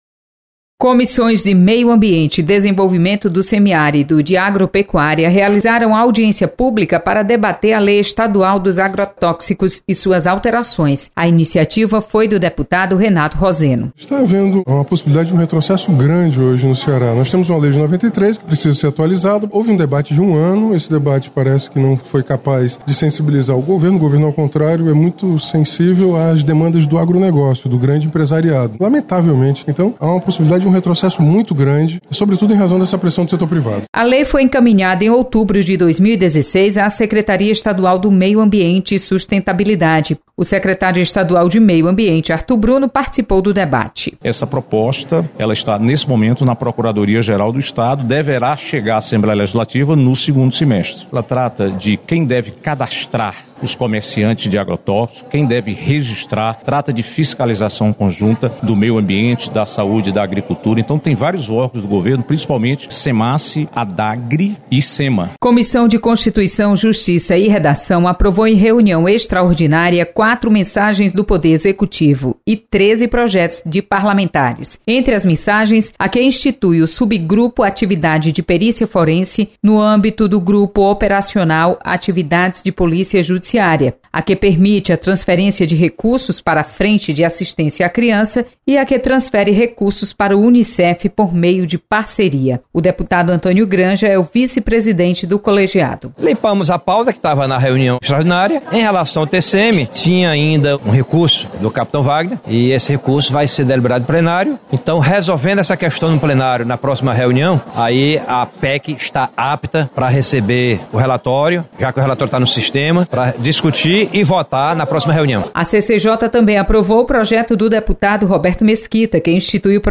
Você está aqui: Início Comunicação Rádio FM Assembleia Notícias Comissões